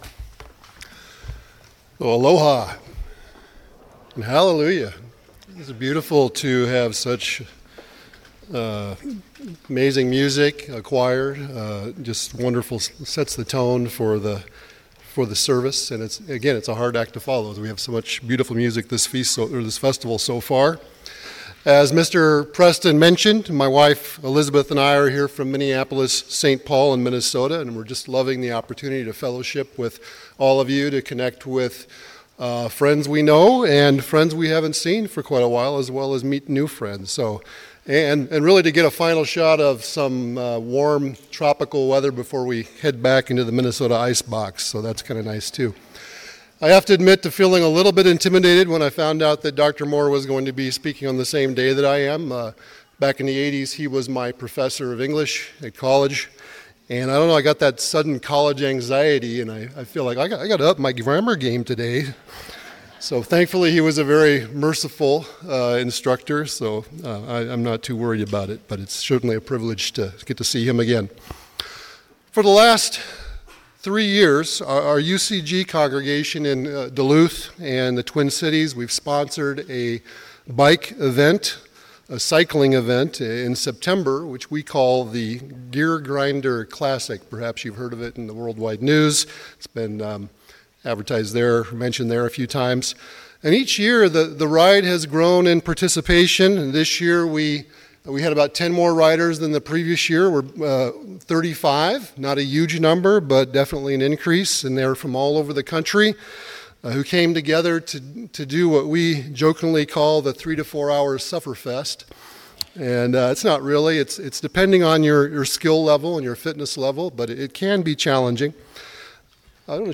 Sermons
Given in Lihue, Hawaii